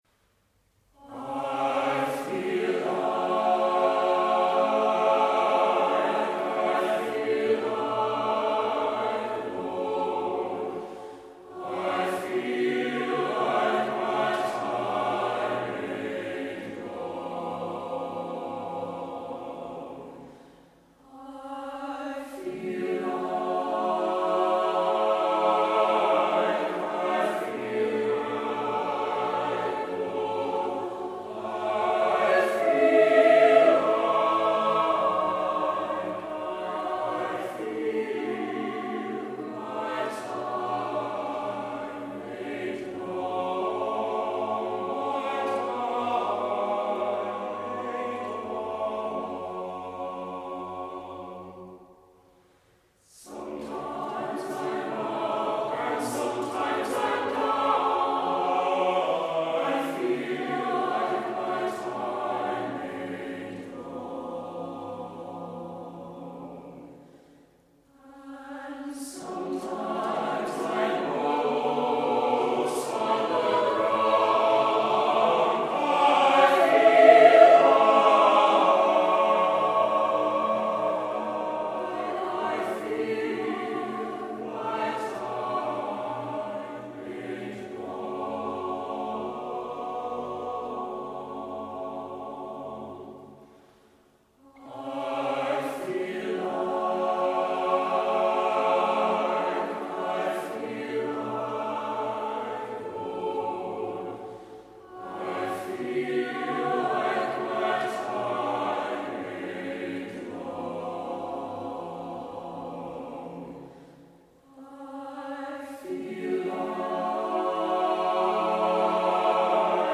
Composer: Spirituals
Voicing: SATB a cappella